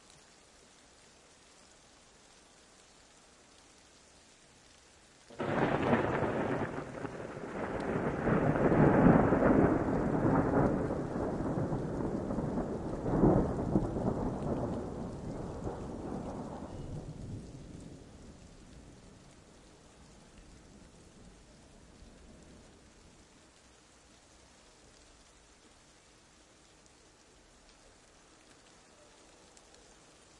描述：雷暴于2081年5月8日在Pécel匈牙利。由SONY icdux512立体声录音机录制。
标签： 暴风 暴雨 气候 雷电 暴雨 雷暴 自然 现场录音
声道立体声